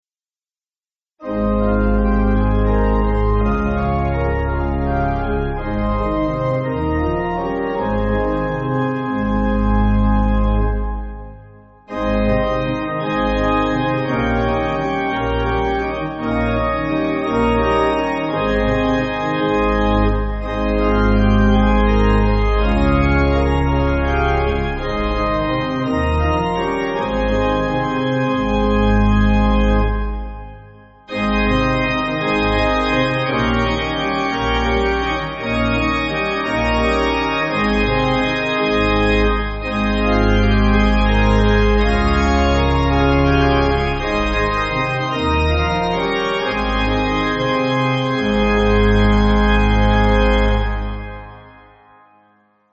Organ
(CM)   2/Ab